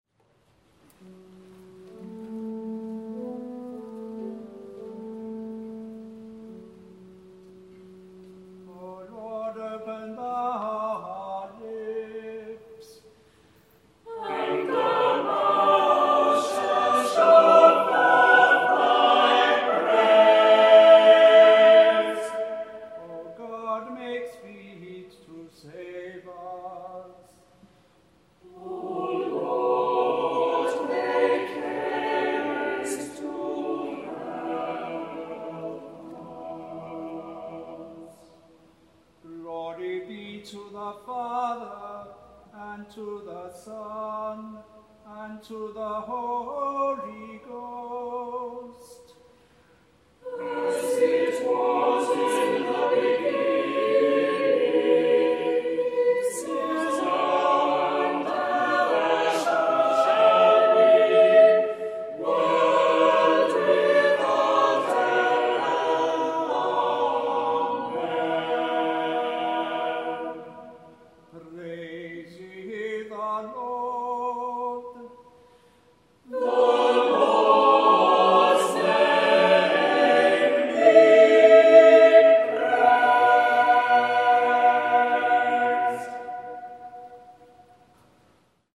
Bernard Rose Preces, sung by the Priory Singers of Belfast at Truro Cathedral